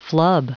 Prononciation du mot flub en anglais (fichier audio)
Prononciation du mot : flub